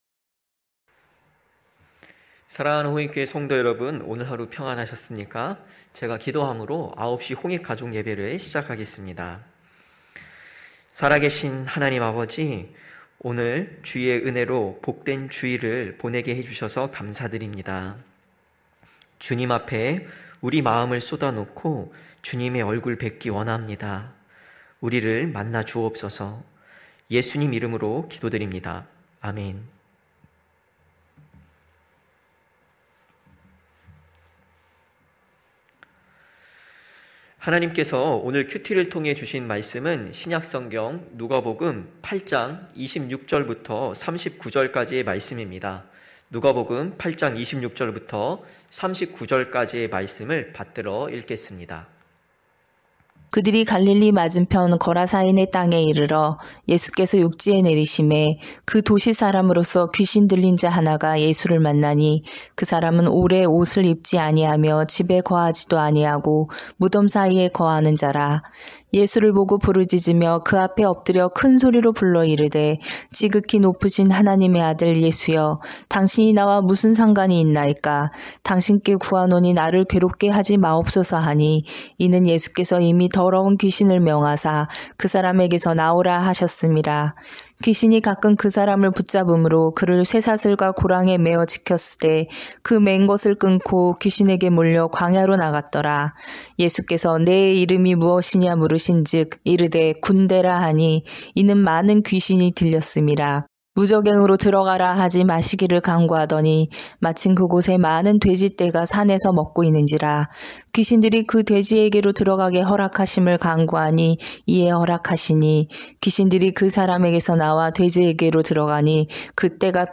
9시홍익가족예배(1월24일).mp3